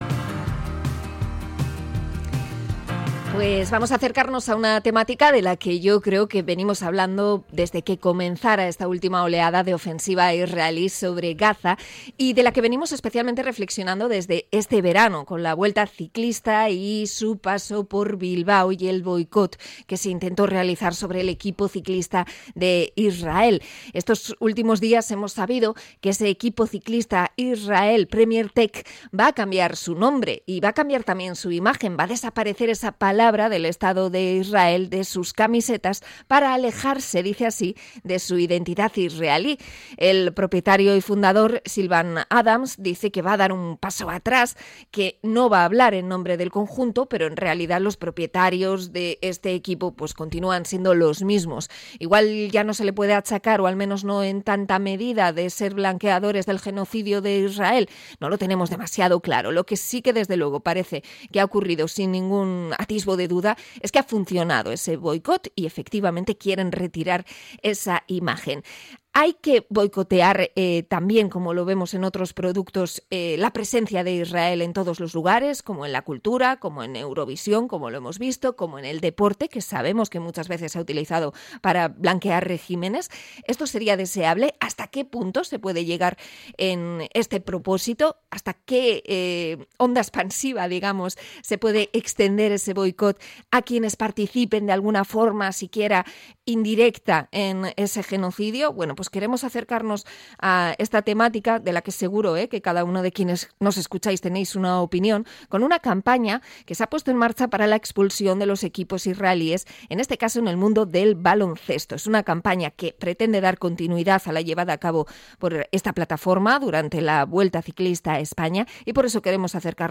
Entrevista sobre el boicot deportivo